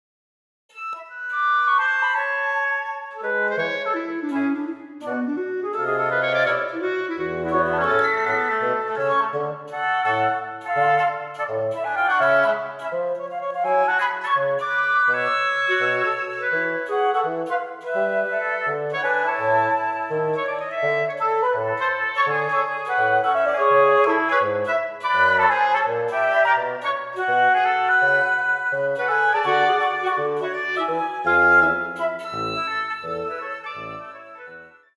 ●デモソングのグラフ（木管楽器）
ダイナミクス傾向が大きい楽器であるため、ビブラートが掛かり始めるまでの時間を短めにしています。
ジャンルにもよりますが、クラリネットはノンビブラートで鳴らすことが多い楽器です。
ダイナミクス傾向が小さい楽器であるため、ビブラートが掛かり始めるまでの時間を少し長めにしています。
ノンビブラート奏法ですが、所々ヒューマナイズ目的でグラフを描いています。
cup_wind_vibd.mp3